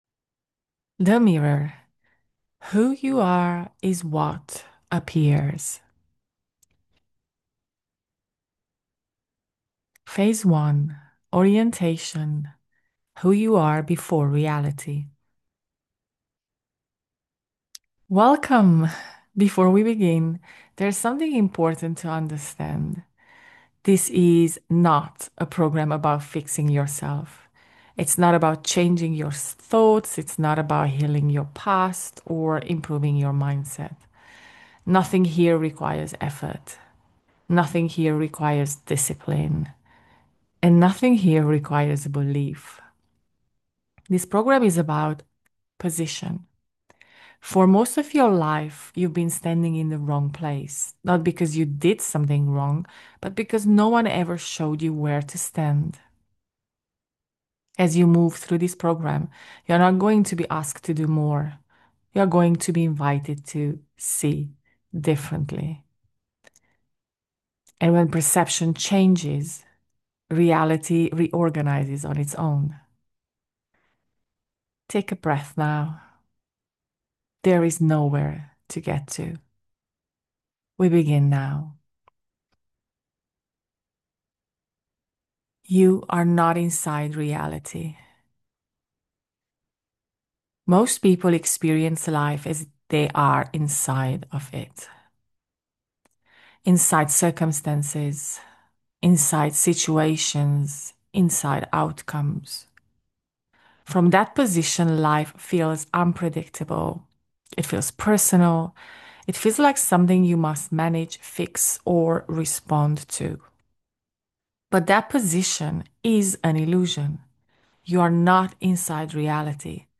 A total of 34 short audios with awareness pauses.